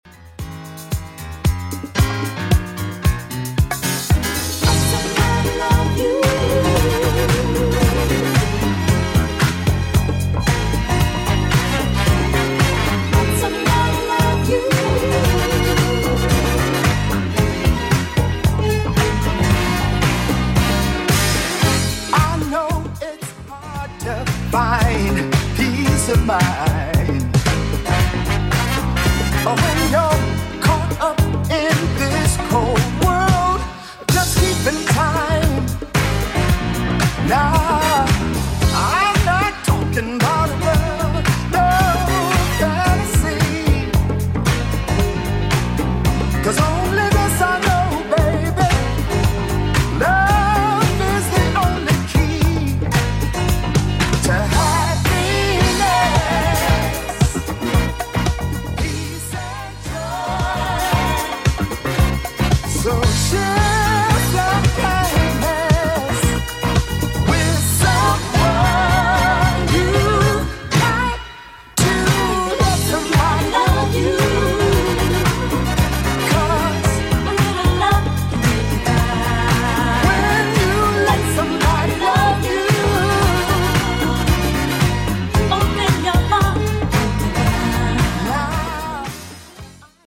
BPM: 113 Time